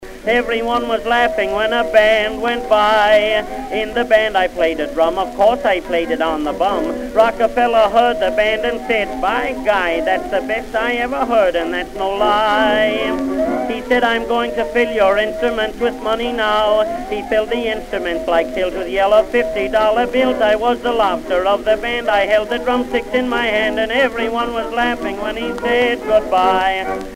Orch. acc.
rapid-fire diction